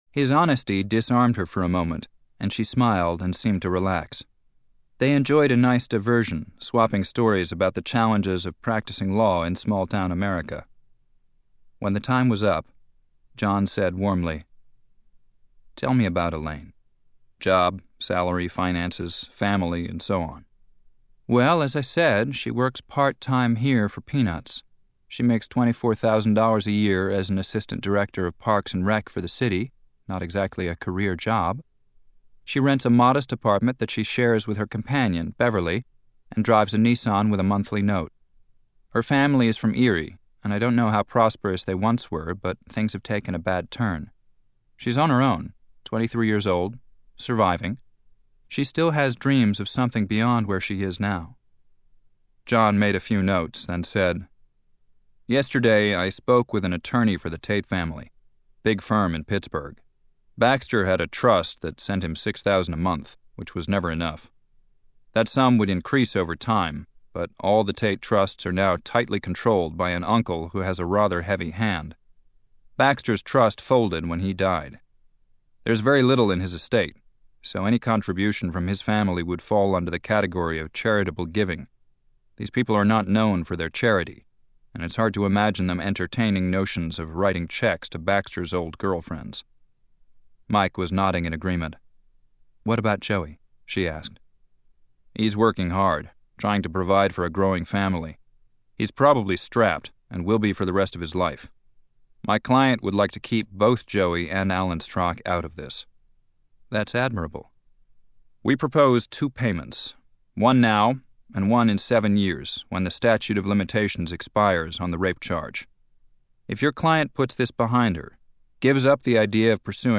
ServeToMe: / 8TB-Media2 / Audio Book / John Grisham - The Associate (2009) 64kbps mp3